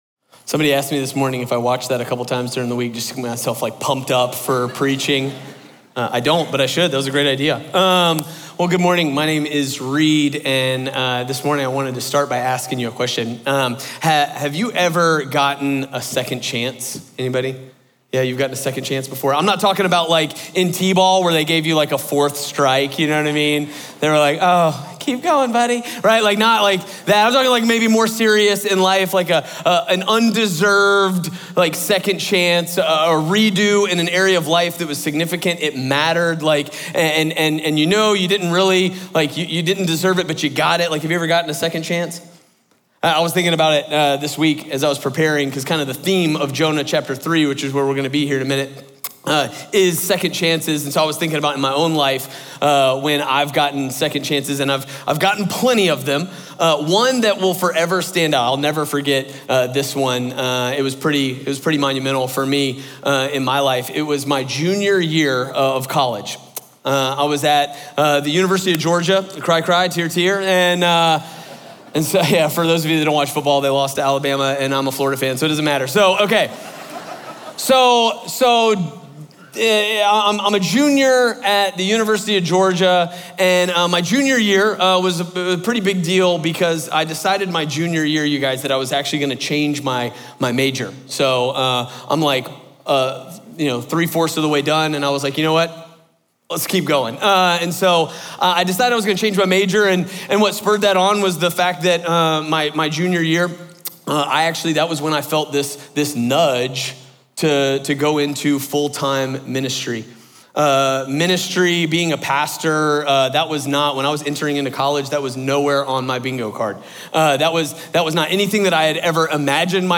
Through Jonah’s obedience and Nineveh’s repentance, this sermon highlights three core truths: God can use anyone, God can save anyone, and God’s grace is for everyone.